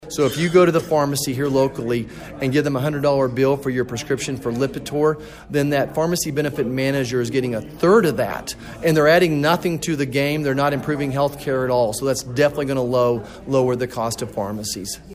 MANHATTAN — Congressman Roger Marshall was back in Manhattan Saturday, hosting a town hall discussion with about two dozen constituents at the Sunset Zoo’s Nature Exploration Place.